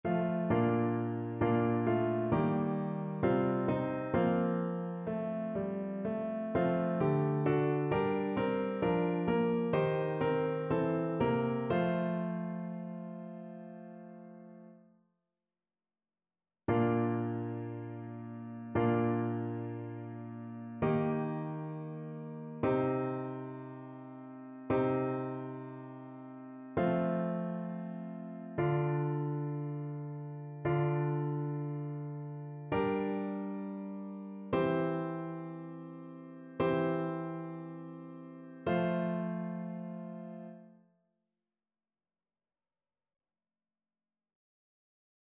Chœur
annee-b-temps-ordinaire-6e-dimanche-psaume-31-satb.mp3